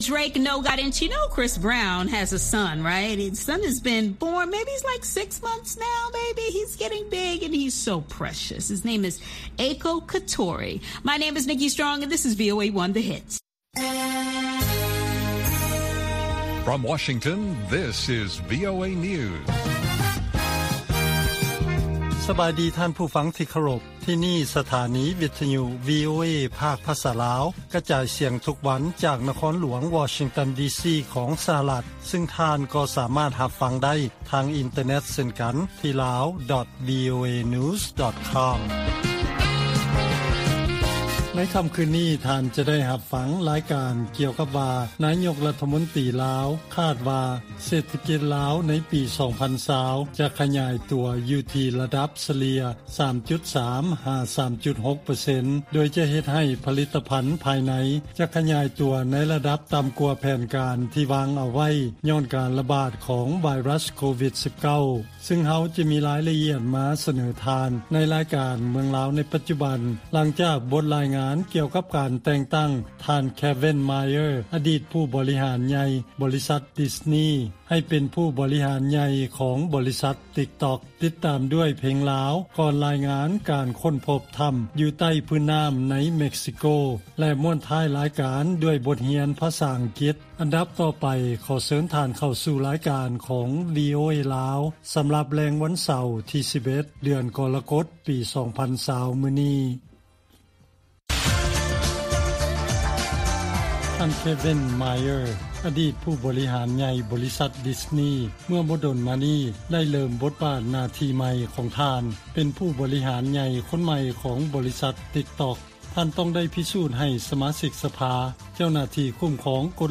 ລາຍການກະຈາຍສຽງຂອງວີໂອເອ ລາວ
ວີໂອເອພາກພາສາລາວ ກະຈາຍສຽງທຸກໆວັນ. ຫົວຂໍ້ຂ່າວສໍາຄັນໃນມື້ນີ້ມີ: 1ນາຍົກລັດຖະມົນຕີຄາດວ່າ ເສດຖະກິດລາວໃນປີ 2020 ຈະຂະຫຍາຍຕົວເພີ້ມຂຶ້ນໃນອັດຕາສະເລ່ຍ 3.3 ຫາ 3.6 ເປີເຊັນ.